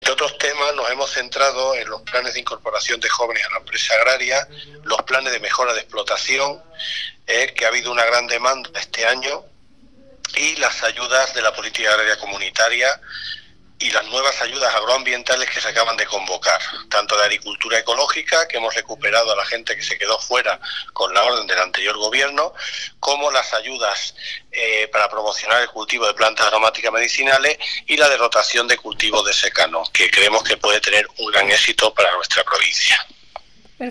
Consejería de Agricultura, Ganadería y Desarrollo Rural Miércoles, 8 Febrero 2017 - 3:00pm El director provincial de Agricultura, Medio Ambiente y Desarrollo Rural en Cuenca, Joaquín Cuadrado, destaca el incremento de las solicitudes de ayudas a la incorporación en la provincia. corte_cuadrado_reunion_ocas.mp3 Descargar: Descargar Provincia: Cuenca